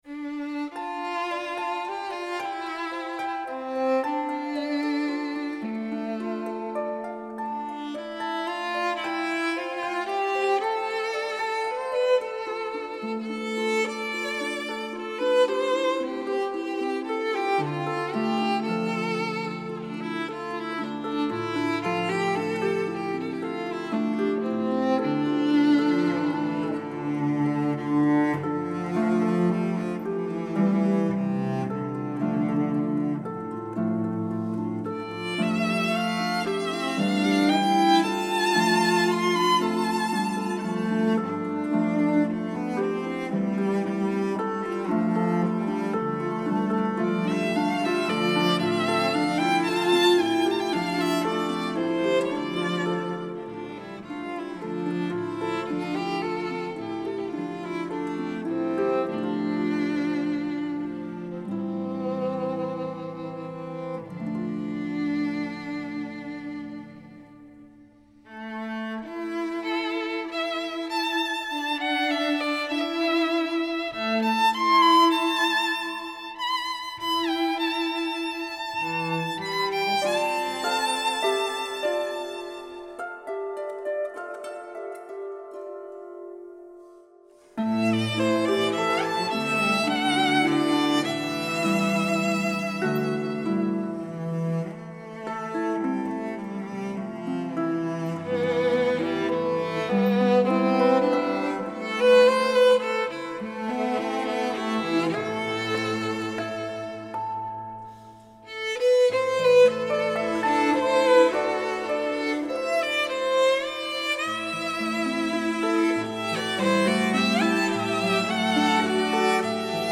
Cello
Violin.